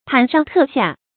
忐上忑下 注音： ㄊㄢˇ ㄕㄤˋ ㄊㄜˋ ㄒㄧㄚˋ 讀音讀法： 意思解釋： 謂心神不定，恐懼不安。